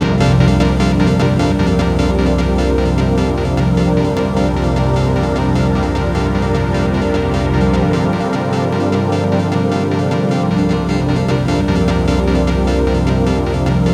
SEQ PAD01.-L.wav